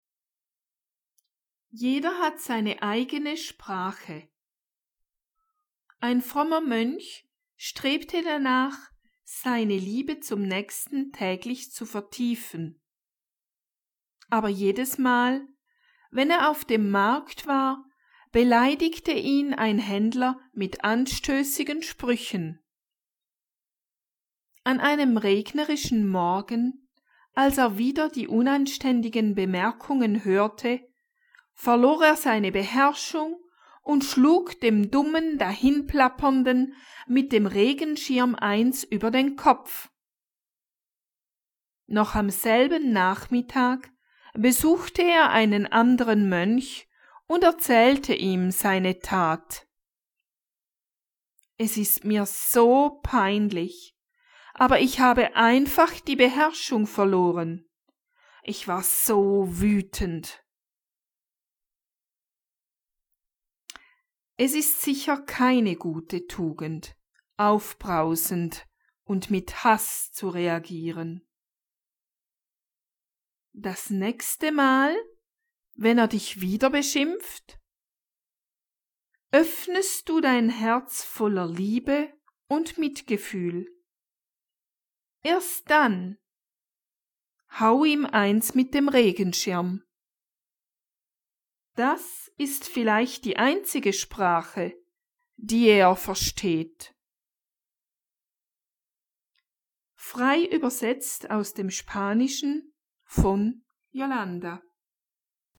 esta historia narrada